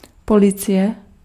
Ääntäminen
Ääntäminen France: IPA: [pɔ.lis] Haettu sana löytyi näillä lähdekielillä: ranska Käännös Ääninäyte Substantiivit 1. policie {f} 2. pojistka {f} Suku: f .